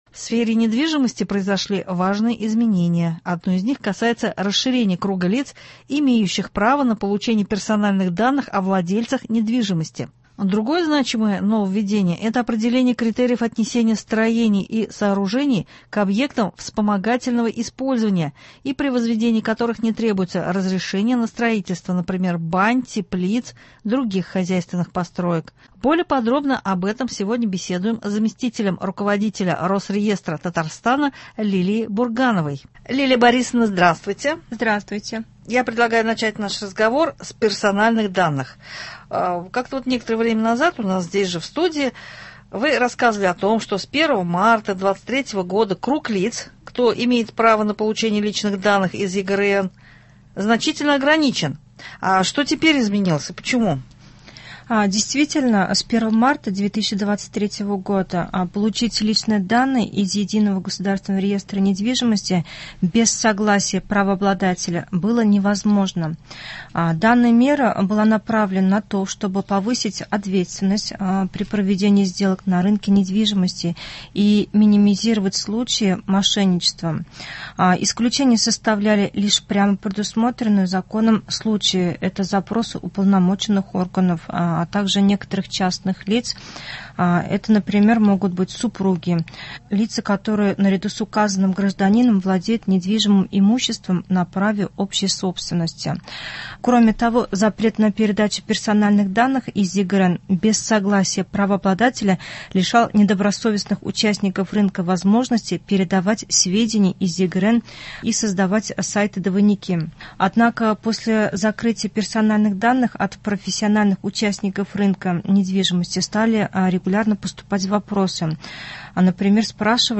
Аудиорепортаж: